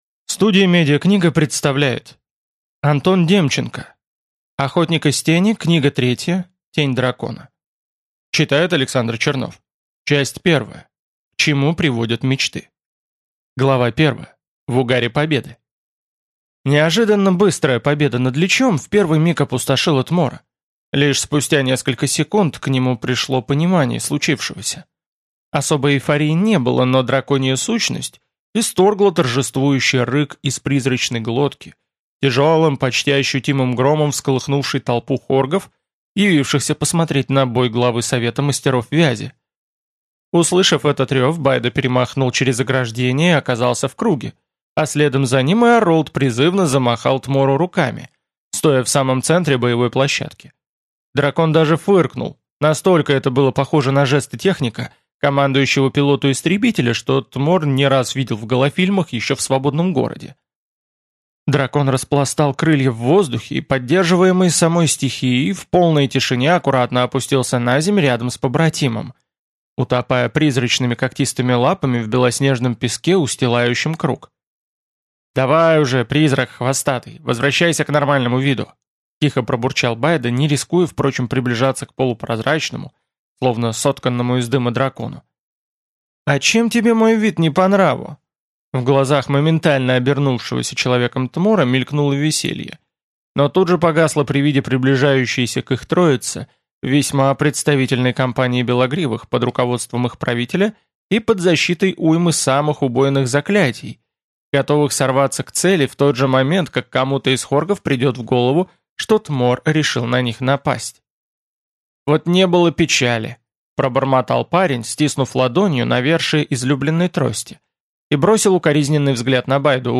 Аудиокнига Тень Дракона | Библиотека аудиокниг